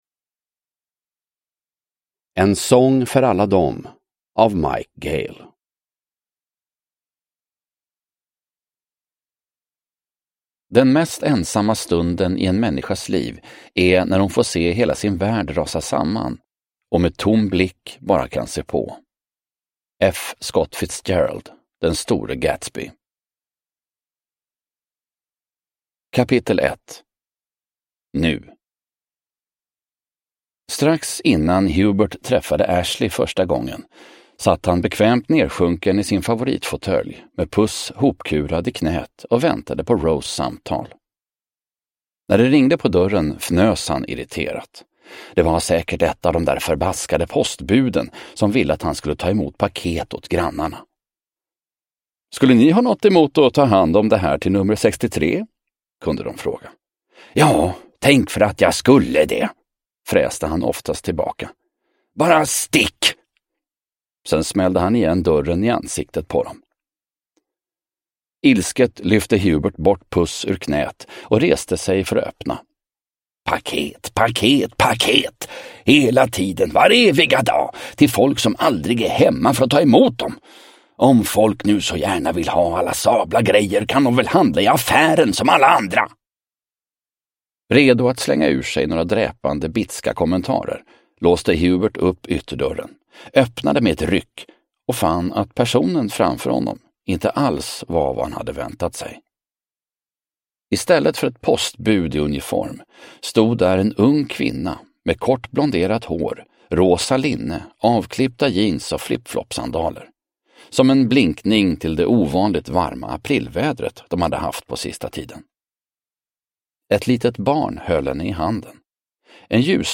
Uppläsare: Fredde Granberg